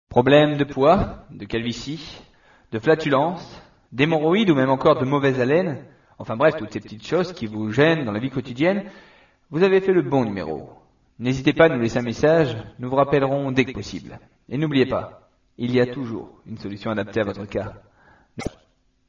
Messages pour répondeur
Un docteur vous propose de laisser vos questions ...